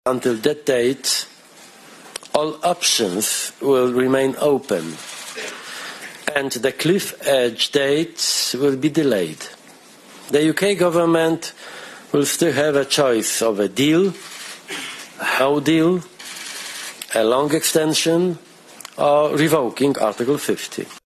Speaking in Brussels last night, the European Council President Donald Tusk says the UK will have to decide the future of Brexit by then……………..